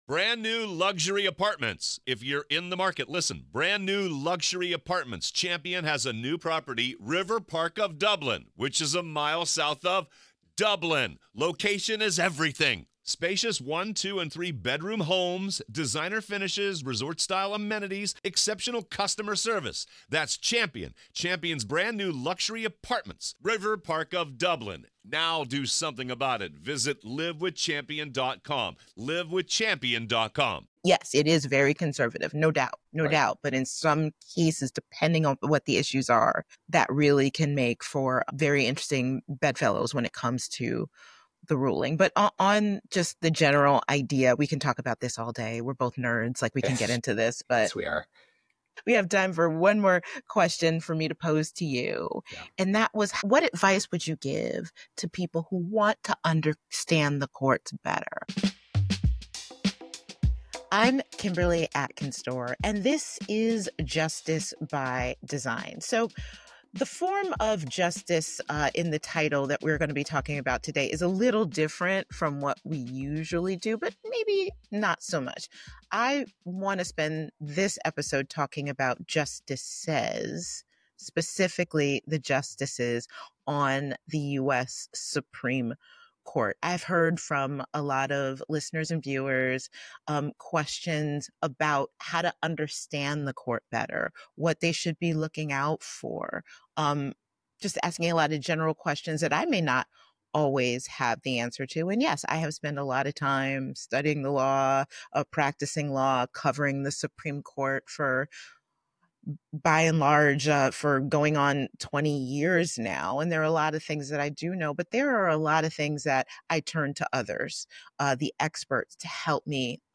They discuss the current state of the Supreme Court, focusing on the religion docket, the evolution of First Amendment jurisprudence, and the significant role of amicus briefs. The conversation also highlights the often-overlooked importance of state courts in shaping legal outcomes and addresses common misconceptions about the judiciary.